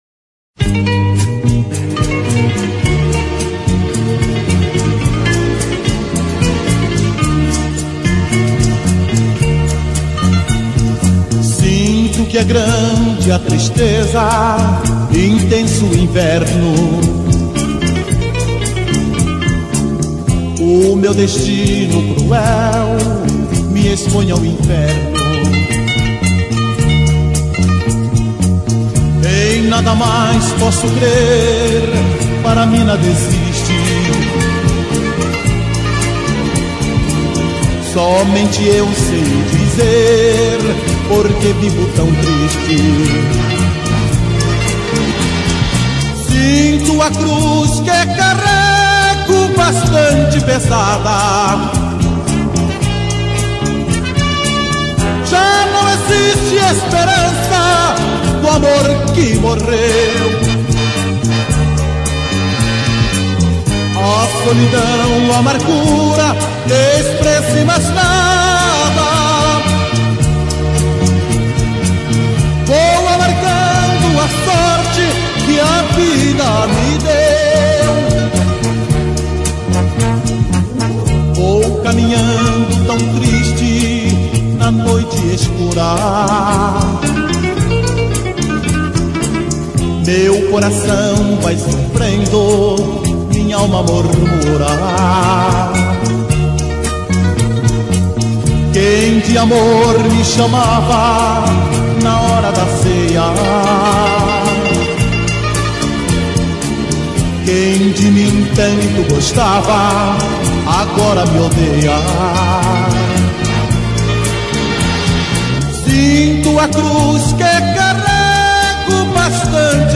Bregas